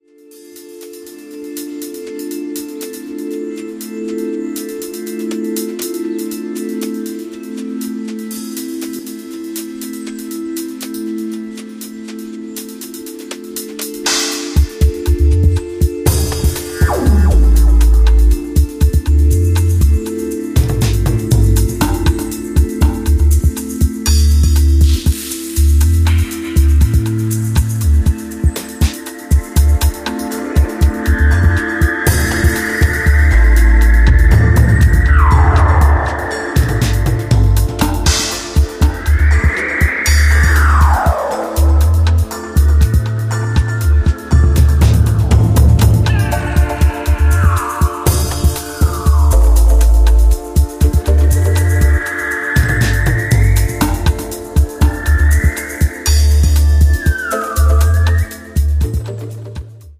An electronic experimentation in forward-th... more...